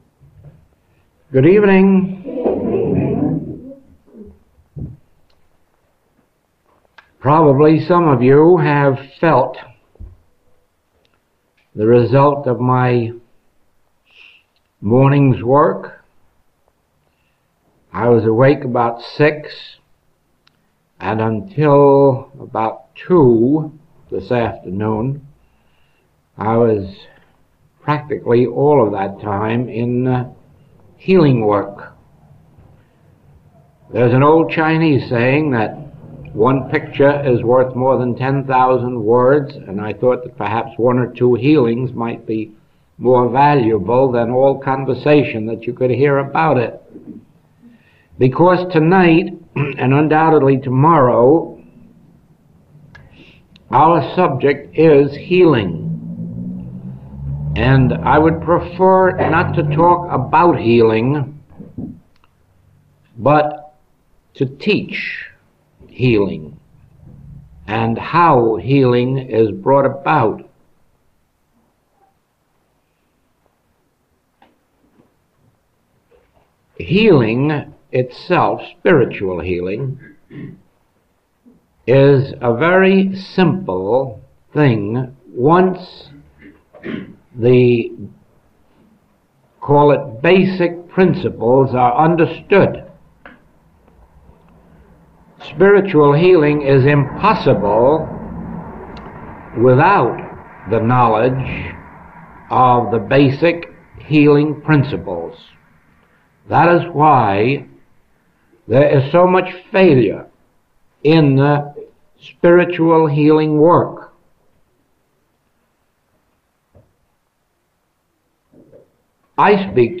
Recording 203A is from the 1958 Adelaide Closed Class.